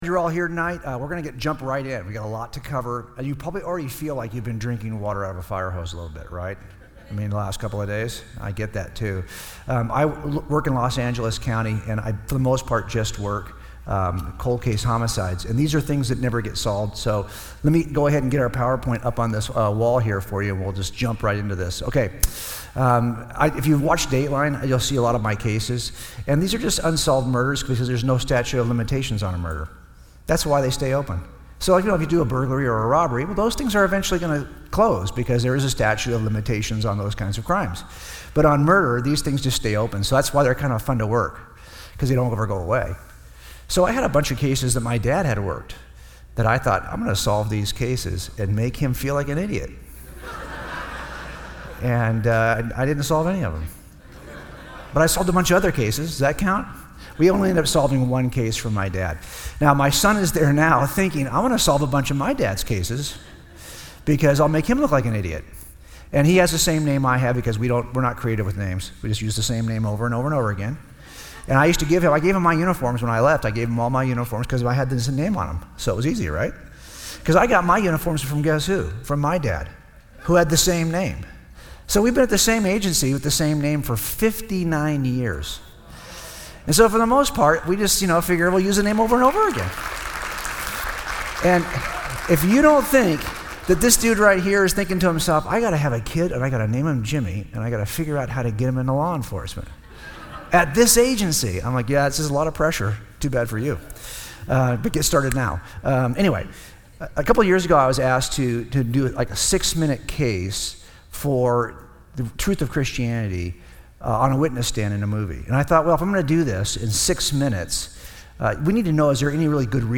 Insight Is 2020: Apologetics Conference